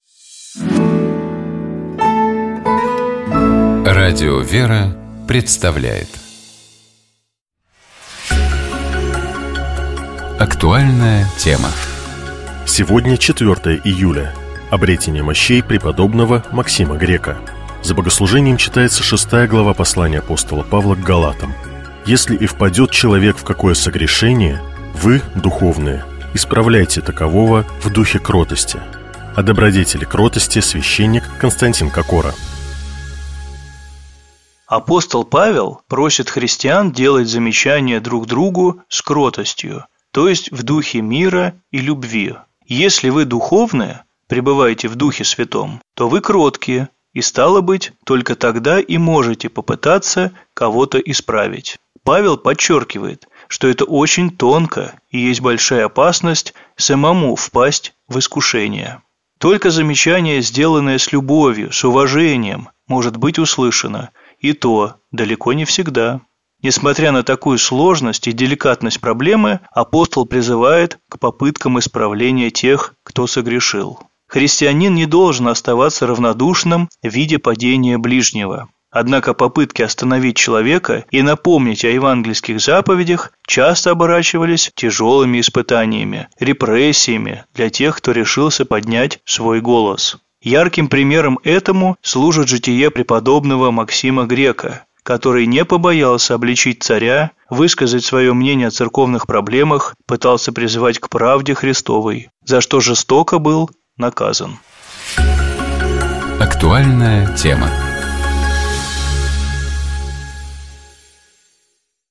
О добродетели кротости, — священник